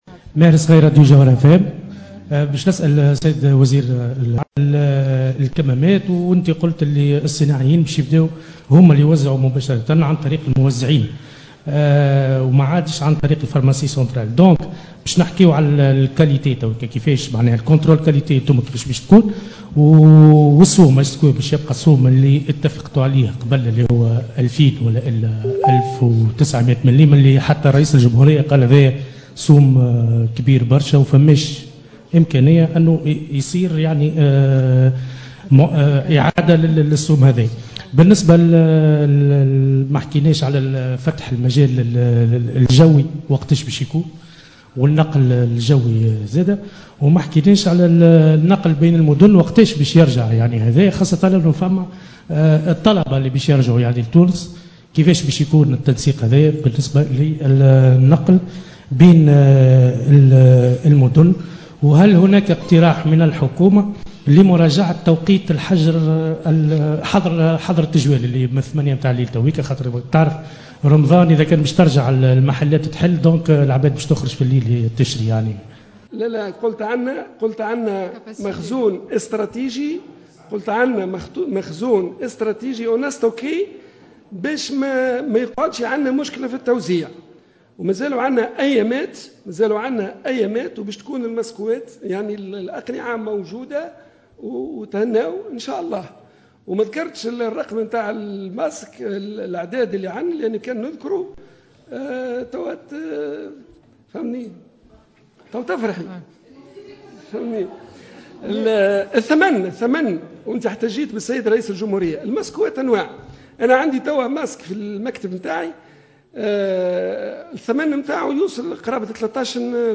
لندوة صحفية عُقدت اليوم بقصر الحكومة بالقصبة